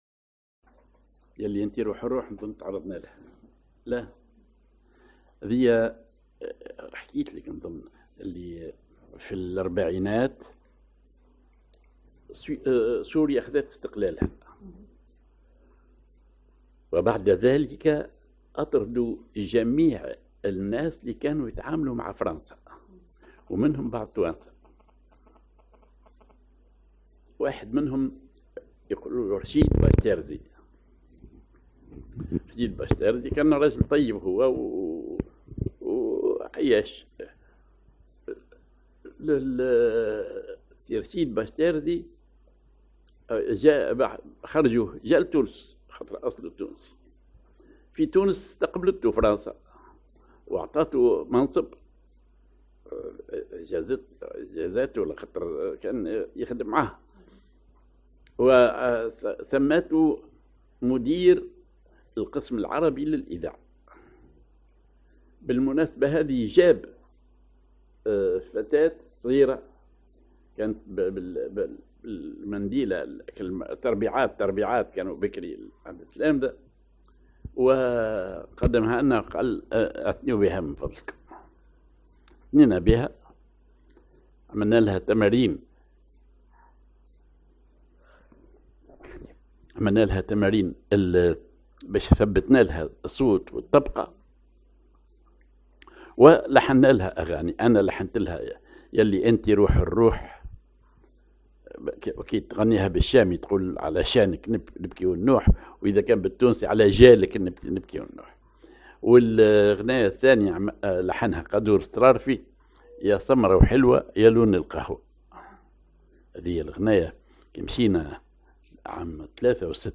Maqam ar بياتي
Rhythm ar نصف لوحدة
genre أغنية